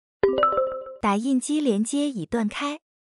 新提示音+语音 3-1.mp3